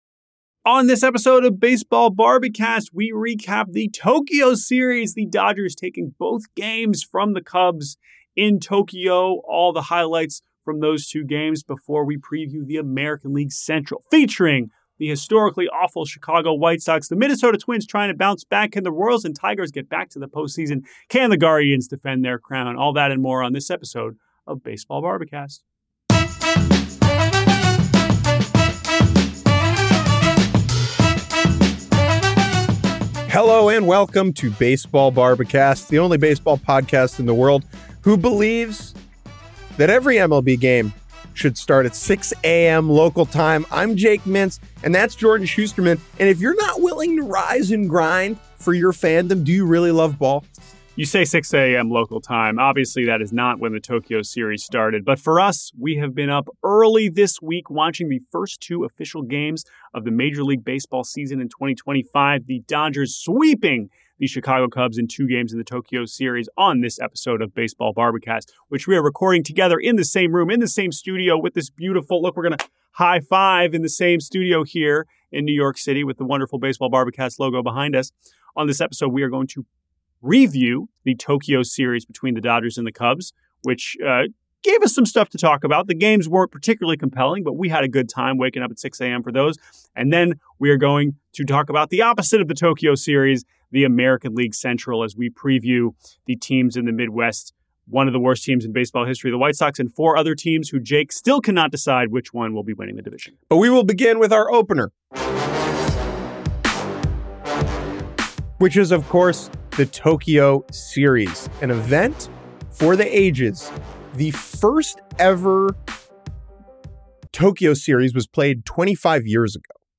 in person in the Yahoo NYC studio to record the pod. The two recap the Tokyo Series between the Dodgers and Cubs and continue their preview series by looking at the AL Central.